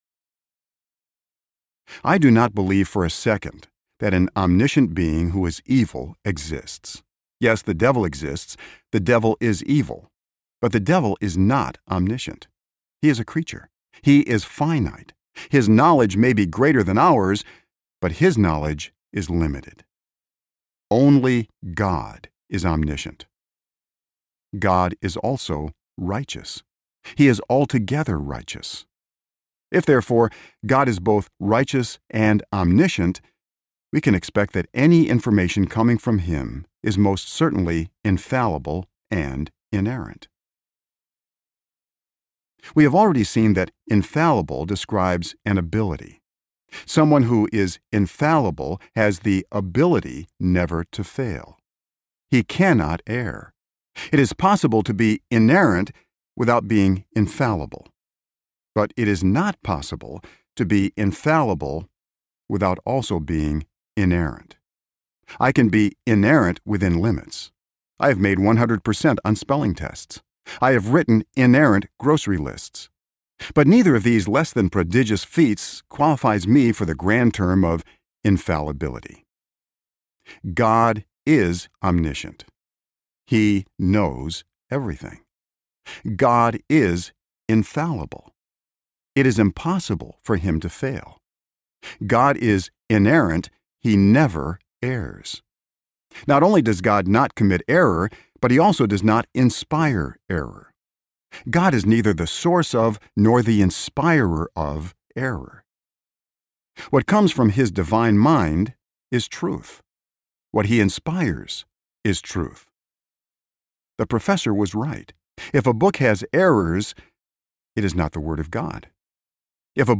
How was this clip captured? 7.05 Hrs. – Unabridged